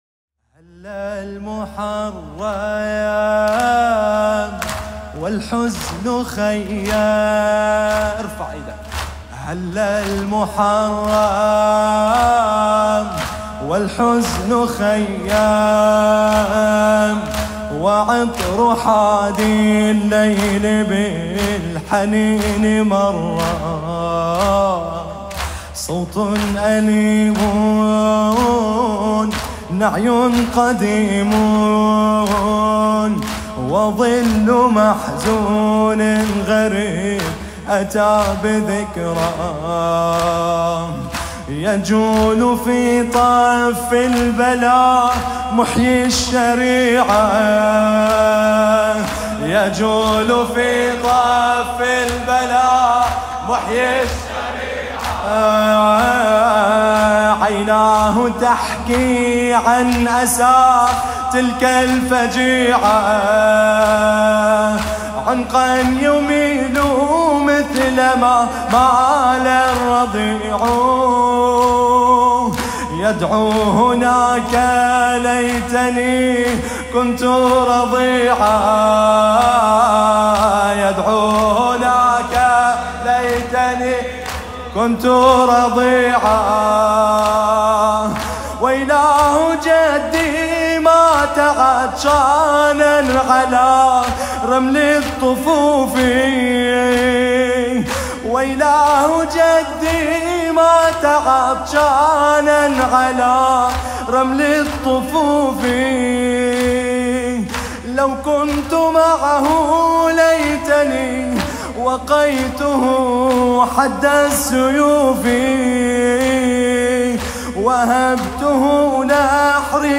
محرم - عاشوراء 1443 ه.ق